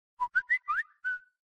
2. Samsung Message Notification Sound
This message tone is smooth and perfect for daily alerts.
samsung_message_notification_sound.mp3